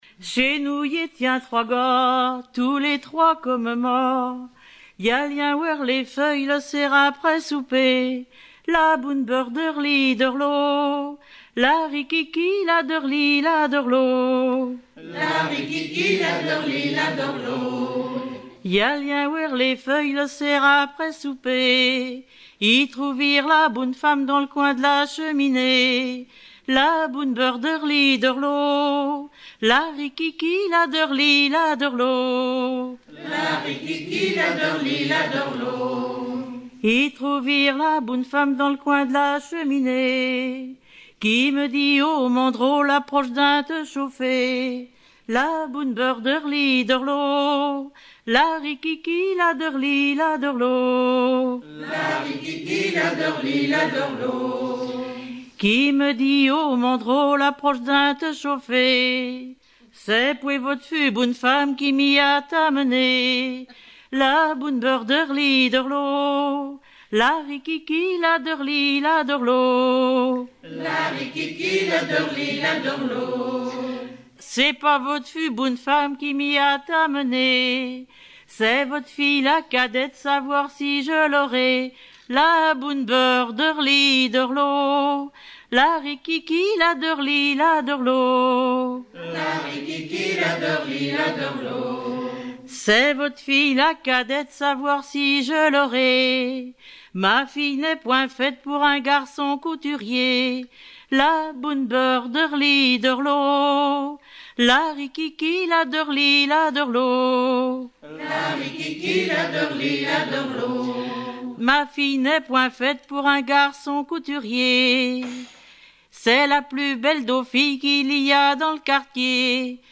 Langue Patois local
Genre laisse
Pièce musicale éditée